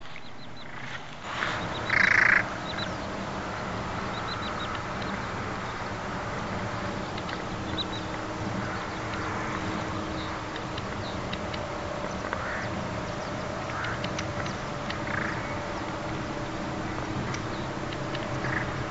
POLLUELA CHICA (Porzana pusilla).
polluela-chica.mp3